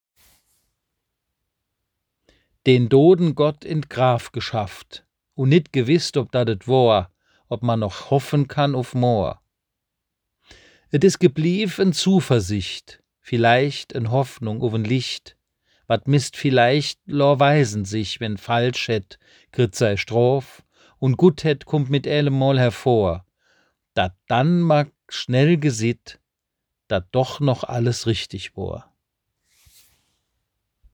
Es enth�lt eine Tonversion der obenstehenden moselfr�nkischen Kreuzwegbetrachtung Sie k�nnen es unter diesem Link abrufen.